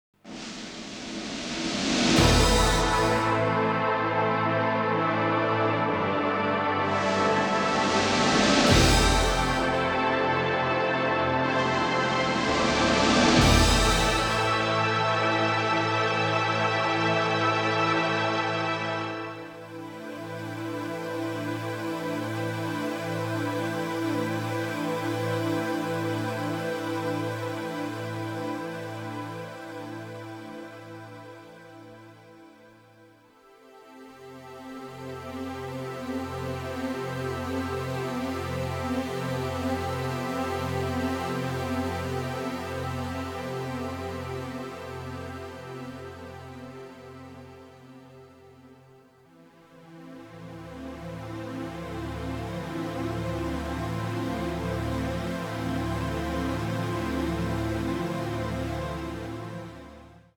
the usual eighties sound of synthesizers
a sort of new-age jazz mood
both are in pristine stereo sound.